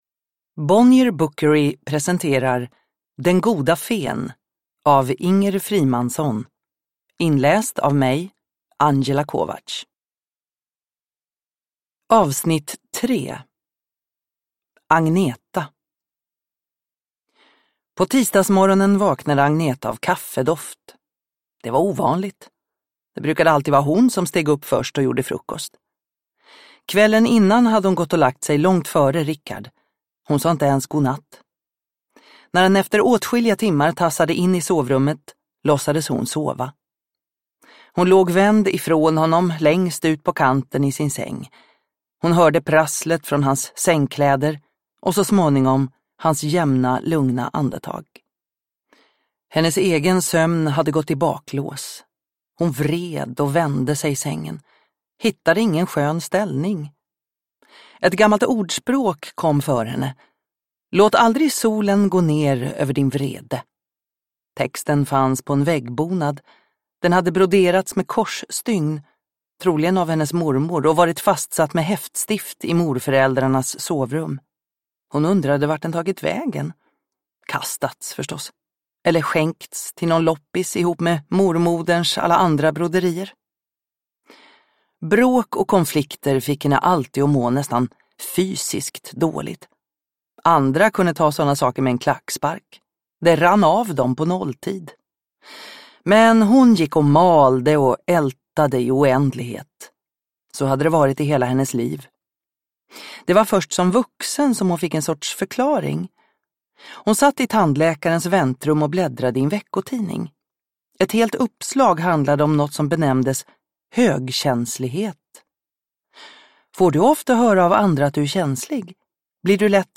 Den goda fen E3 (ljudbok) av Inger Frimansson